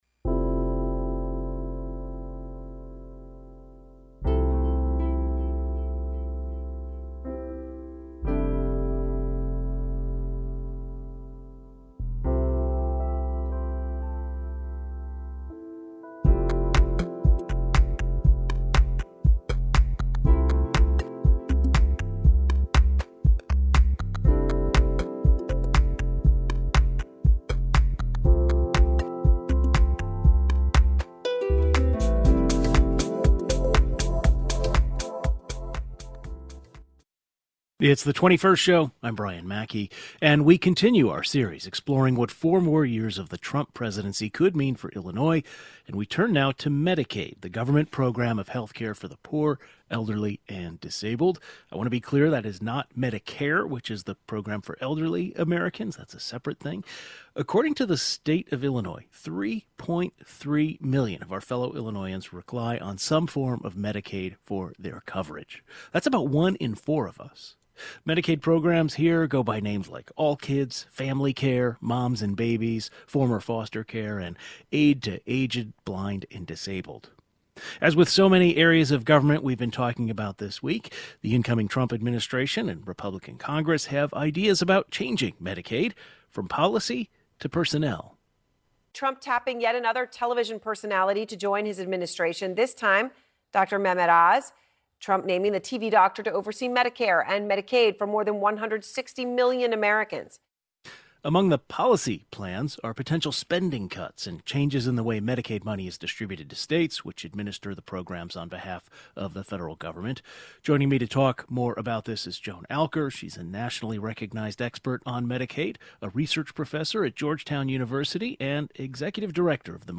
Among the plans are potential spending cuts and changes in the way Medicaid money is distributed to states, which administer the programs on behalf of the federal government. Two experts in economic and public policy join the program to weigh in.